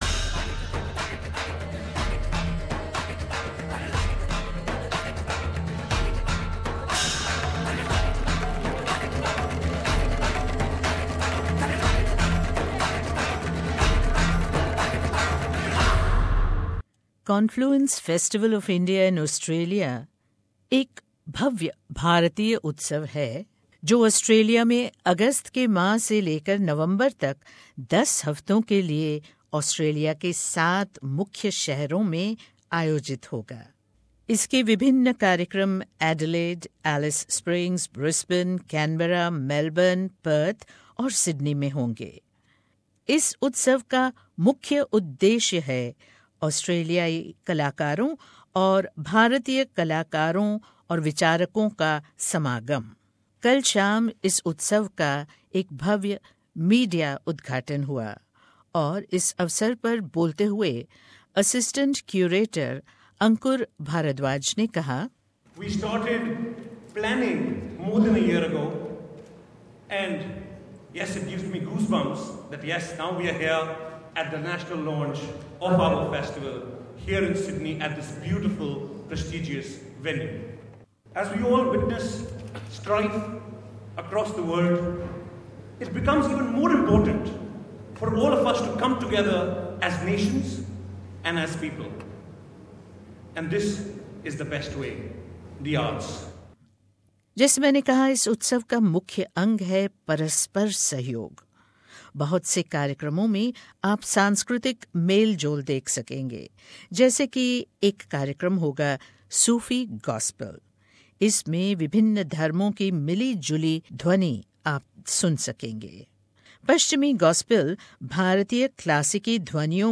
उत्सव के लिए नेशनल मेरीटाइम म्यूजियम में एक पत्रकार सम्मेलन हुआ जिसमें उत्सव की रूपरेखा दी गई। इसका मुख्य उद्देश्य है कला के माध्यम से परस्पर सहयोग और मेल मिलाप। आईये देखते हैं ऑस्ट्रेलिया स्थित भारत के उच्चायुक्त श्री नवदीप सूरी और अन्य वक्ताओं का क्या कहना था।